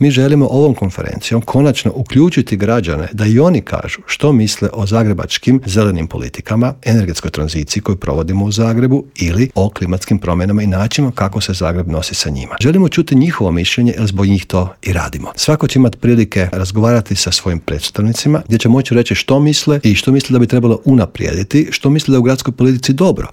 U Intervjuu Media servisa gostovao je predsjednik zagrebačke Gradske skupštine, Joško Klisović, koji je istaknuo važnost konferencije, njezine ciljeve i objasnio koliko je bitna uloga građana.